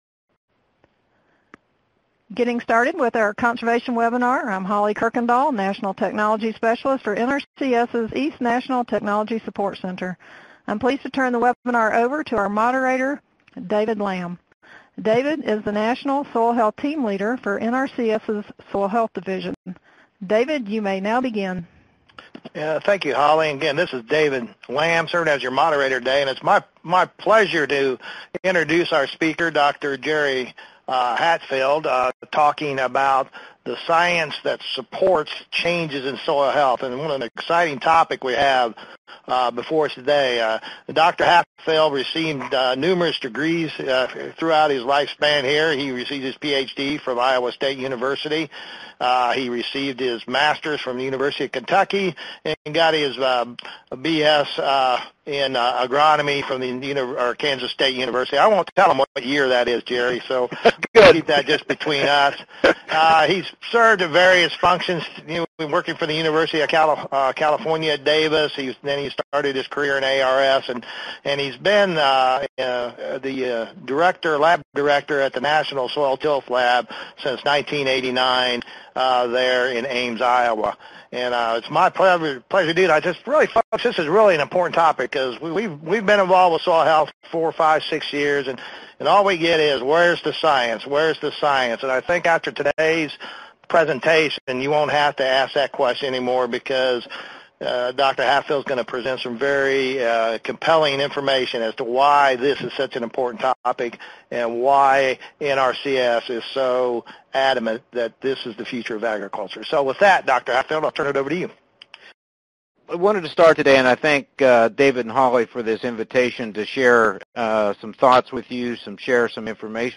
Streaming Podcast Webinar Audio - CEUs and certificates are not available for podcasts.